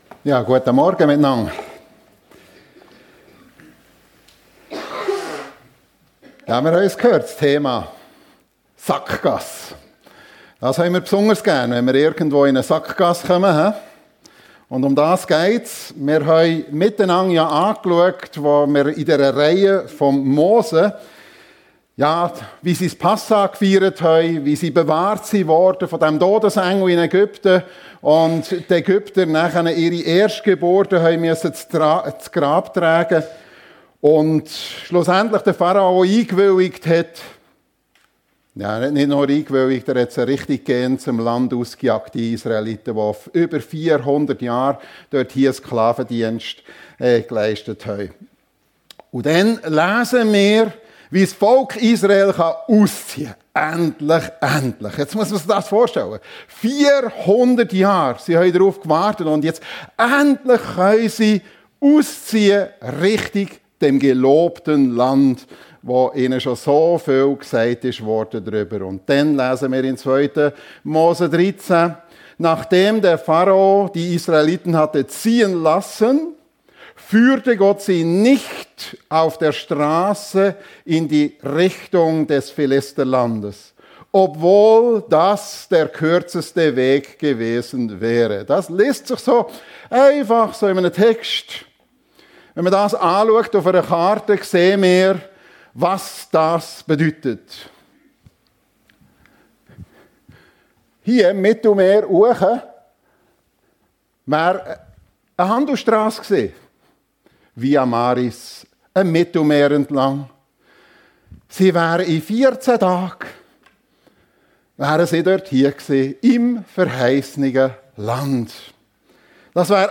Predigt
in der FEG Sumiswald